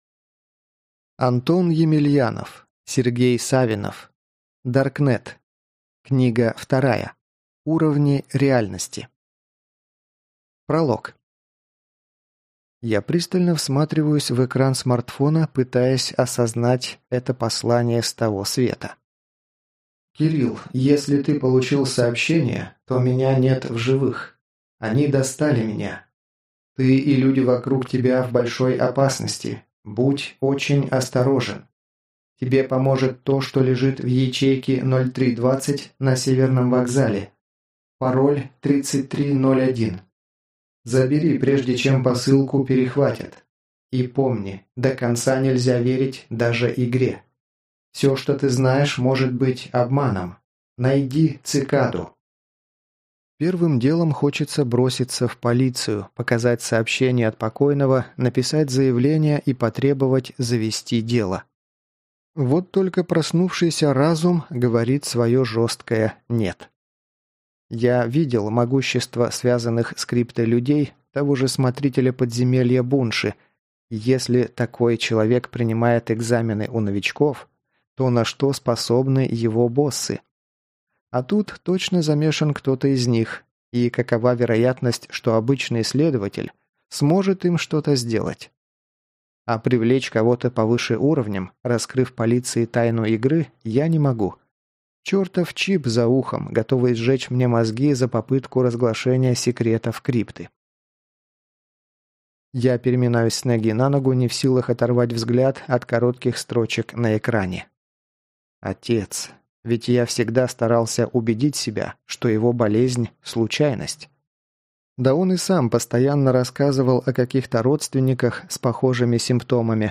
Аудиокнига Даркнет 2. Уровни реальности | Библиотека аудиокниг